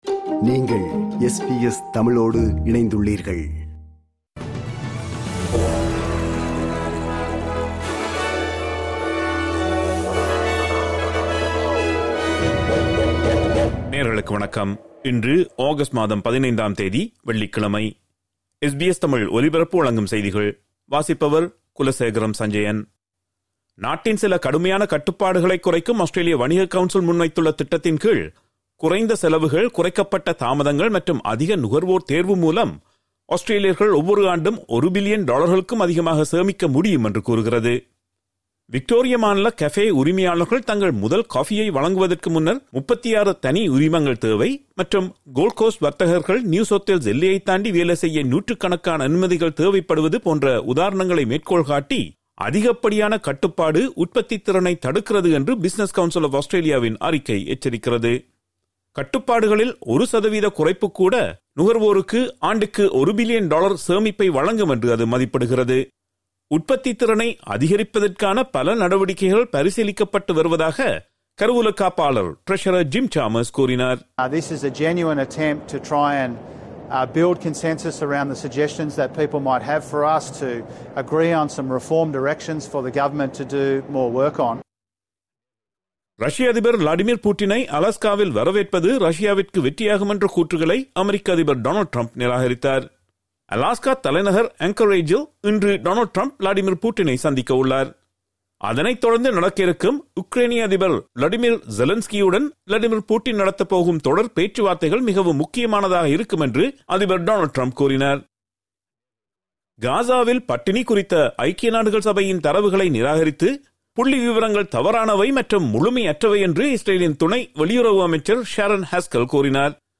SBS தமிழ் ஒலிபரப்பின் இன்றைய (வெள்ளிக்கிழமை 15/08/2025) செய்திகள்.